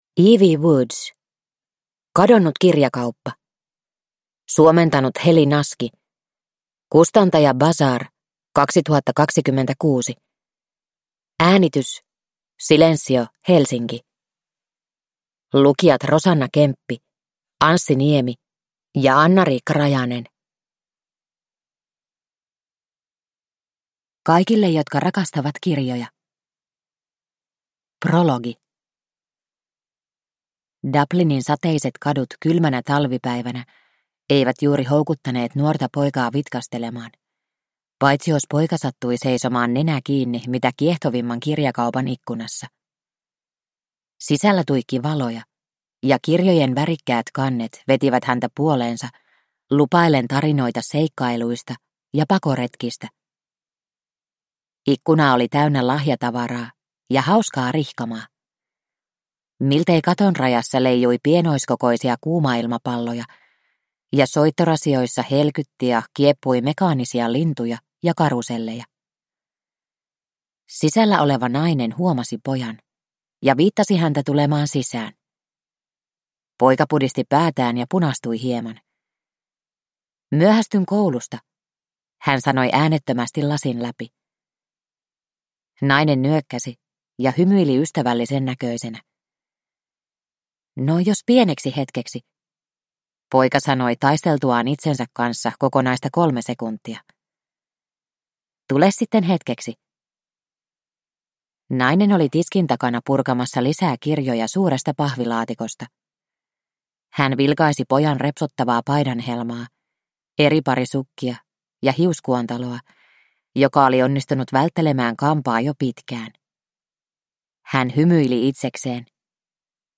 Kadonnut kirjakauppa – Ljudbok
• Ljudbok